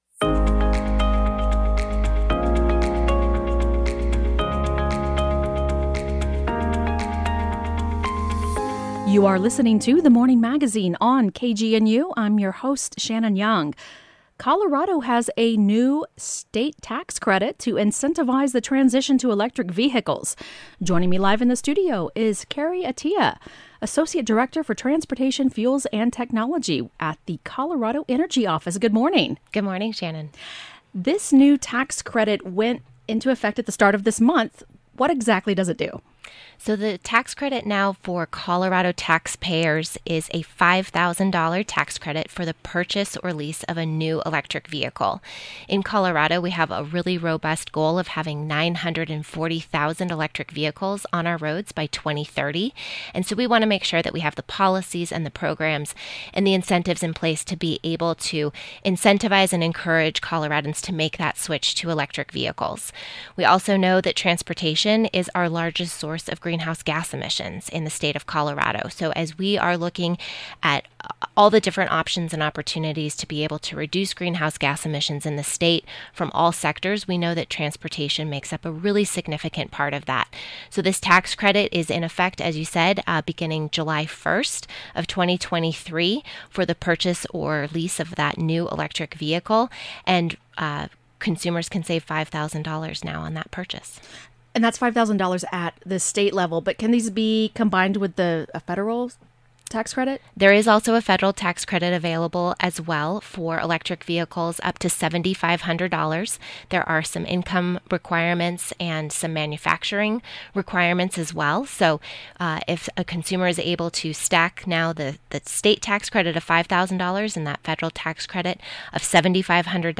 KGNU Broadcast Live On-Air